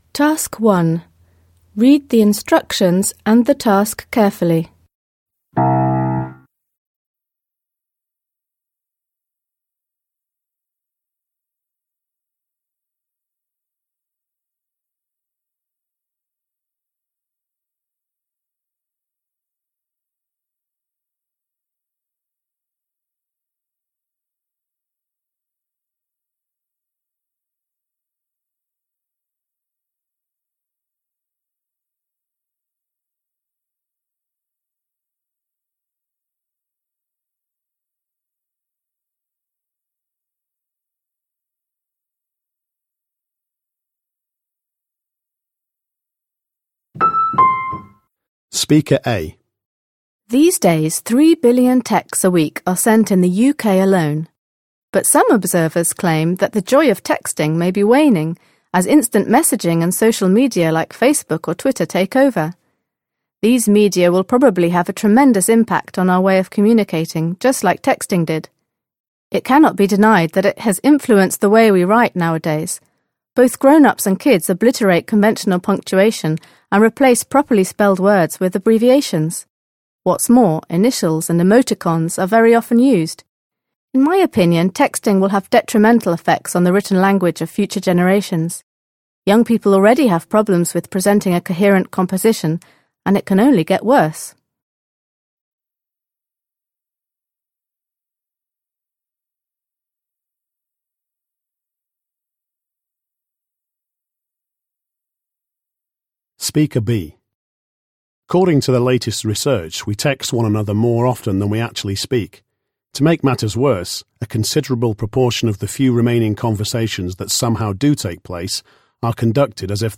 You are going to hear three people talking about the influence of texting and other modern technologies.